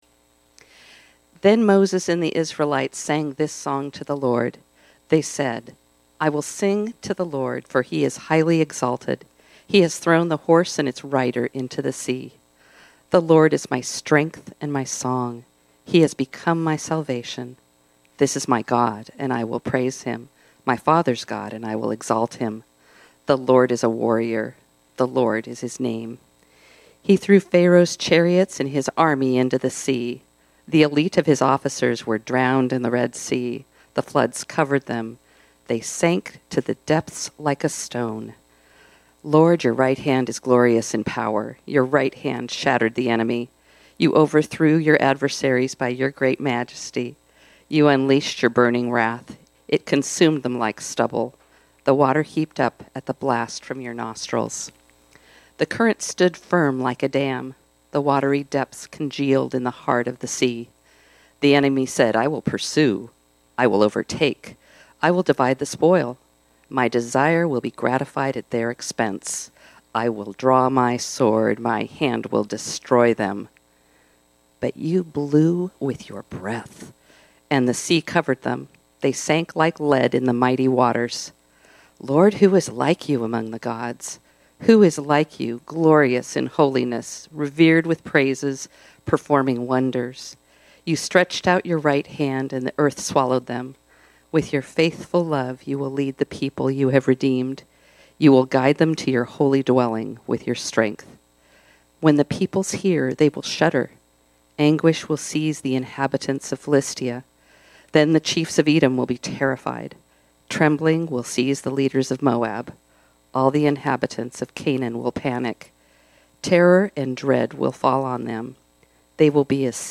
This sermon was originally preached on Sunday, November 26, 2023.